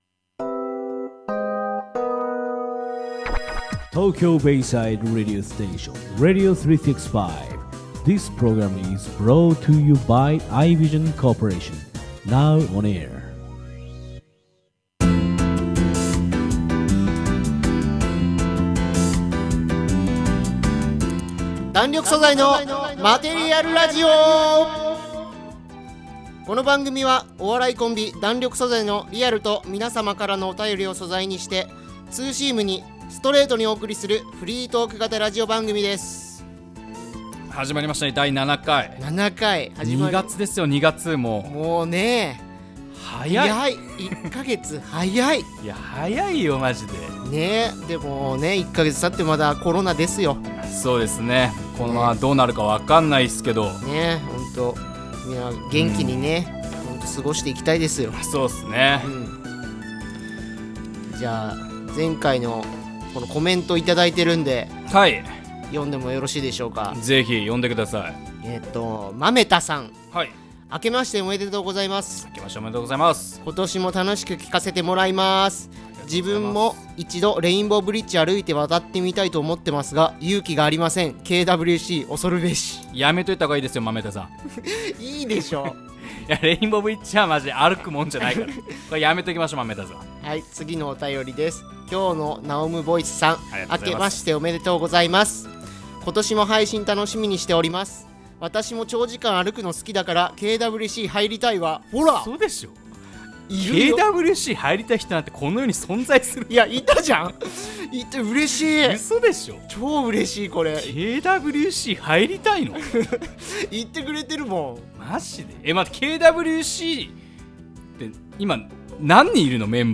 お笑いコンビ弾力素材の7回目のラジオです！！